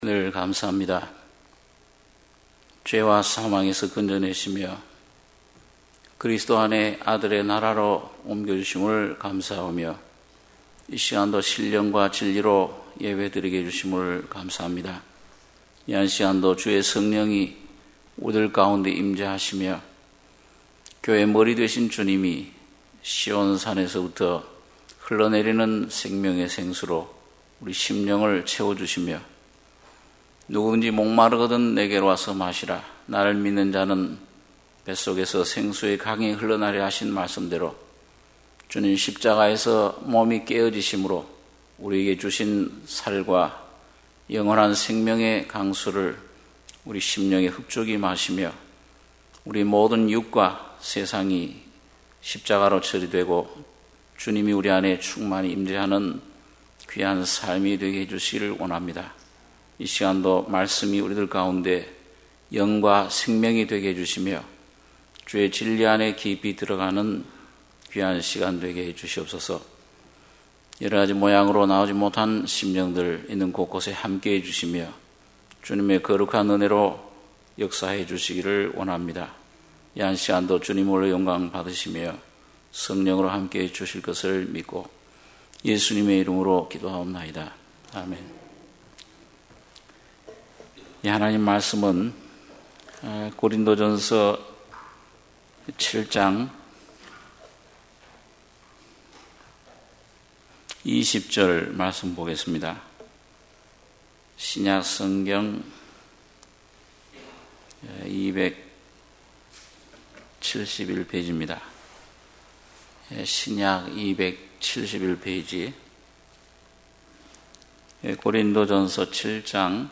수요예배 - 고린도전서 7장 20~31절